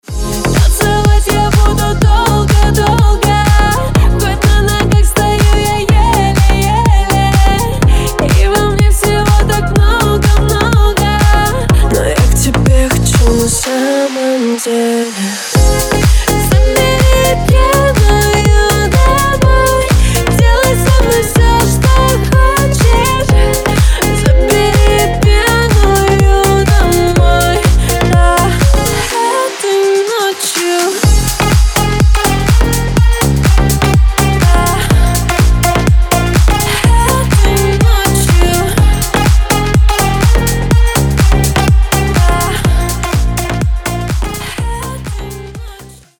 • Качество: 320, Stereo
женский вокал
веселые
Club House
электронная музыка